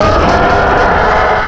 sovereignx/sound/direct_sound_samples/cries/rampardos.aif at master